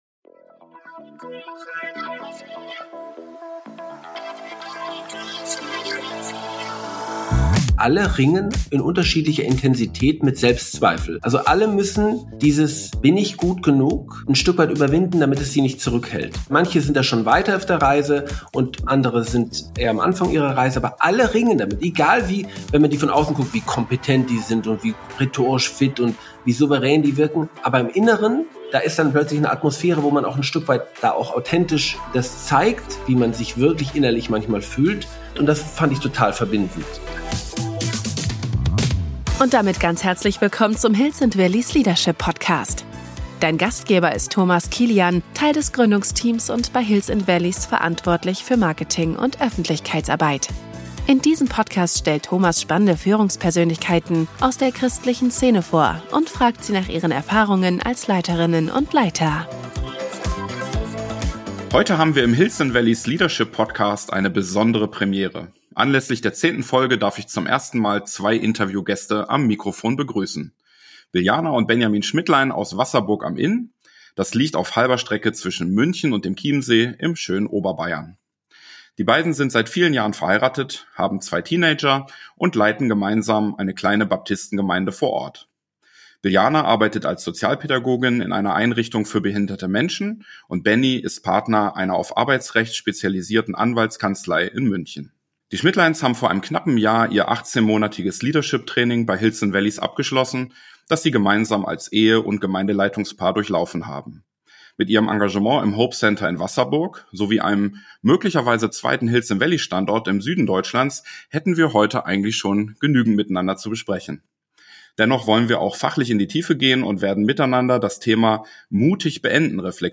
In diesem Gespräch teilen sie, was es heißt, sich als Leiterin oder Leiter ehrlich der Frage zu stellen, ob eine beendete Gemeindearbeit nicht auch ein geistlich reifer Schritt sein kann – und wie man diesen Prozess reflektiert, vorbereitet und verantwortungsvoll gestaltet.